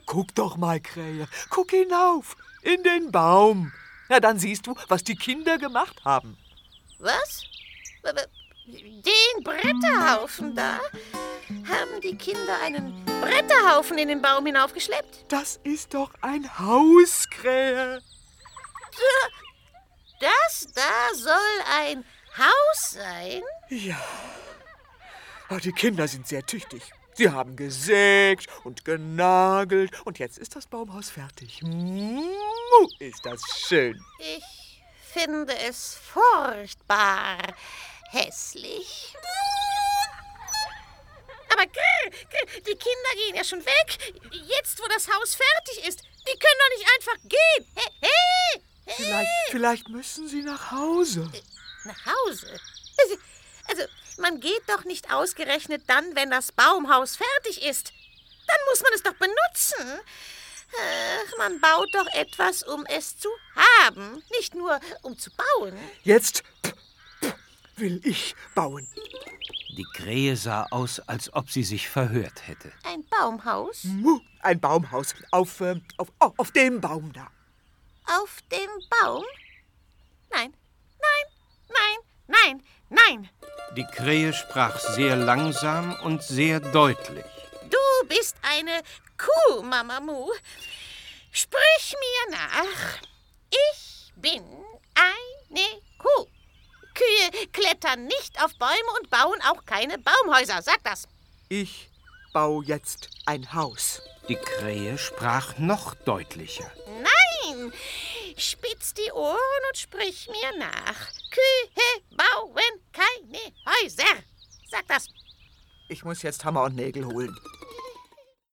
Ravensburger Mama Muh baut ein Baumhaus ✔ tiptoi® Hörbuch ab 4 Jahren ✔ Jetzt online herunterladen!
Mama_Muh-Hoerprobe.mp3